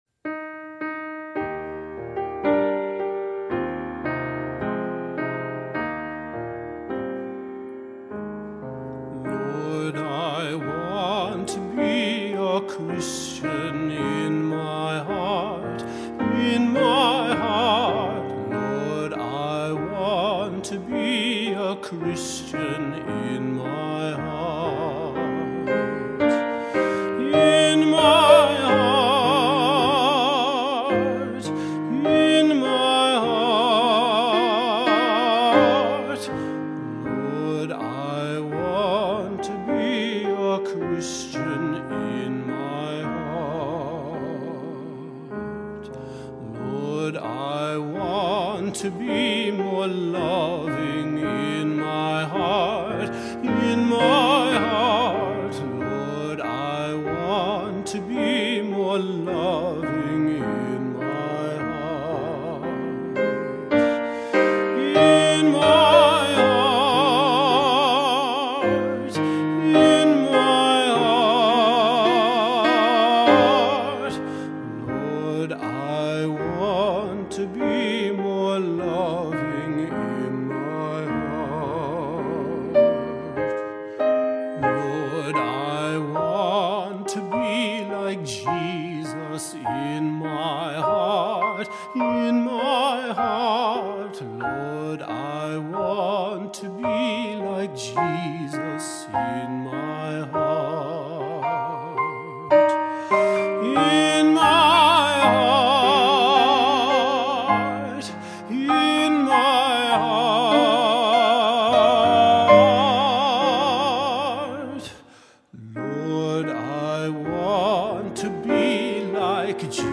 Voix Hautes et Piano